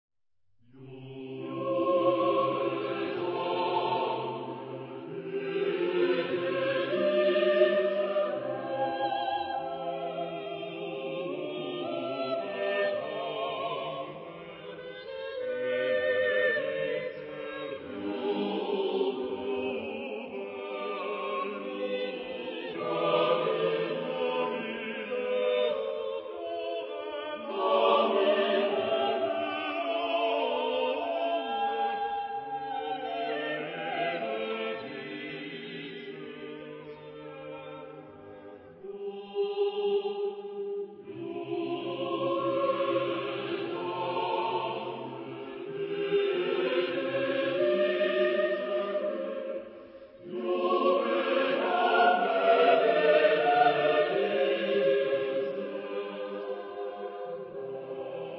Genre-Style-Form: Sacred ; Romantic
Mood of the piece: solemn ; calm
Type of Choir: SATB + SATB  (8 double choir OR mixed voices )
Soloist(s): Sopran (1) / Alt (1) / Tenor (1) / Bass (1)  (4 soloist(s))
Tonality: C major
sung by Kammerchor Stuttgart conducted by Frieder Bernius